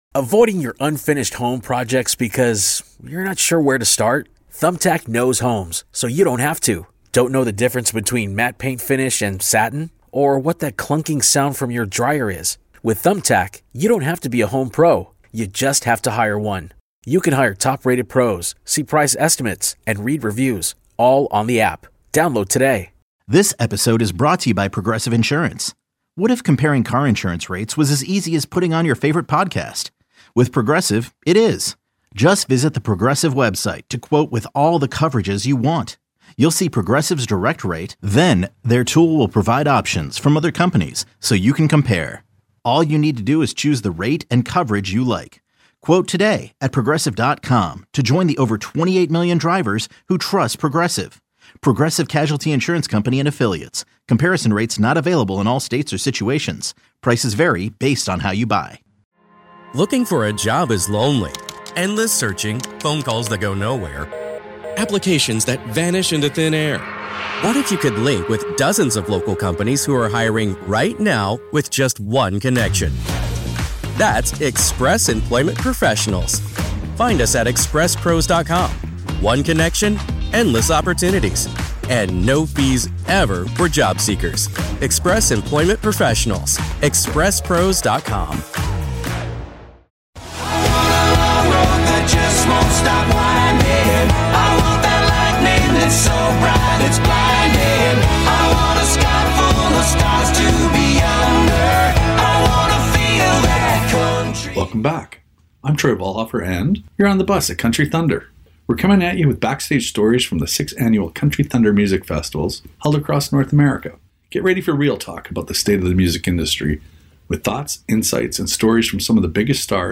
interviews the top country music artists on his tour bus.